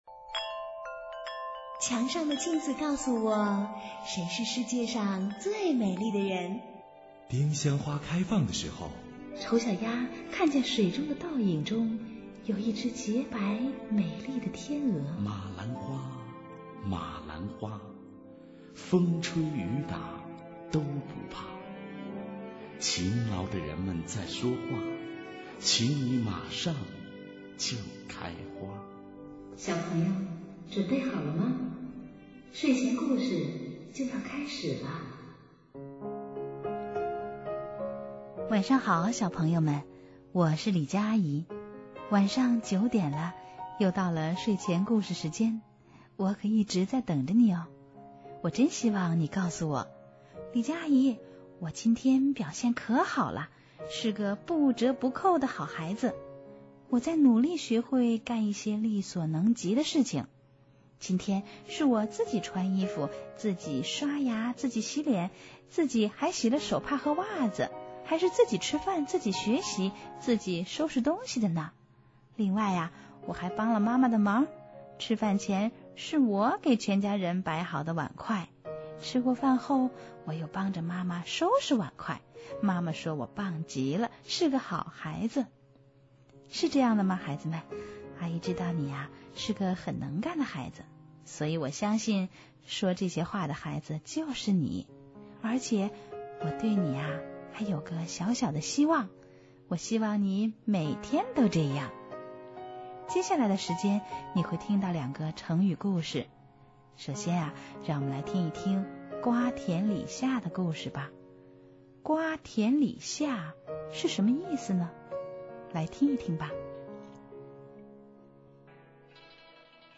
睡前故事